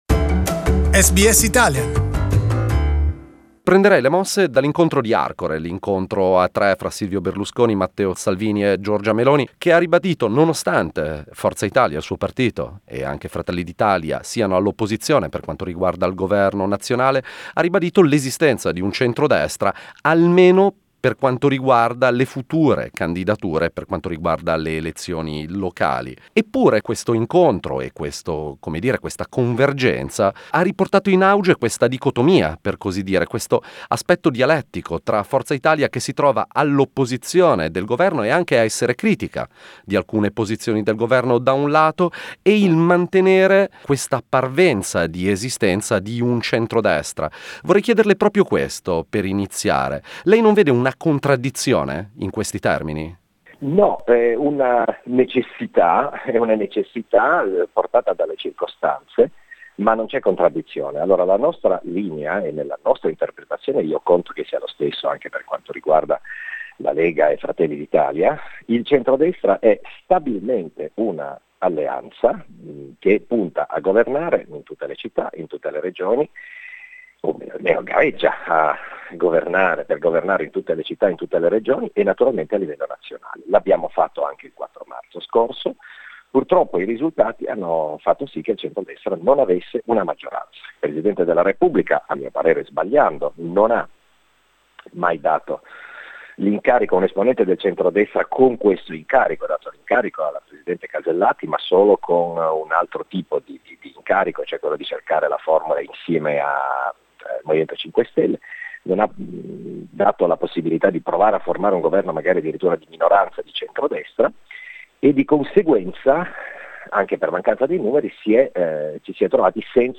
Forza Italia Senator Lucio Malan talks about the apparent contradiction of its party being opposition and yet allied to Salvini's Lega.